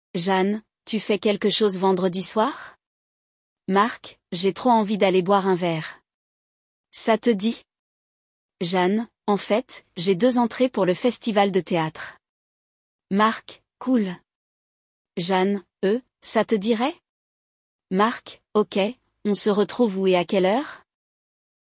Hoje, vamos acompanhar uma conversa entre um casal de amigos em que a moça convida o rapaz para ir ao teatro com ela.